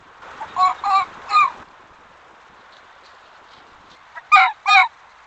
Could it be a contact call of a fox like this.